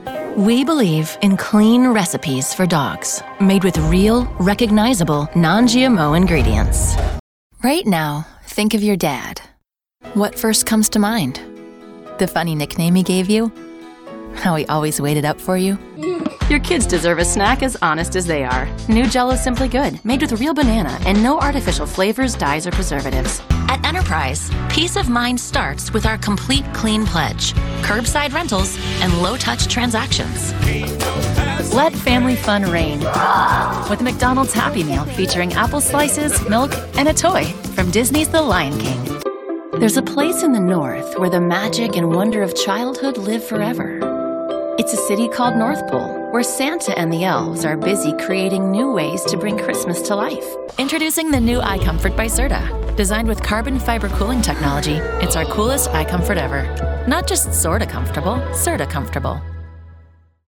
Voiceover : Narration : Women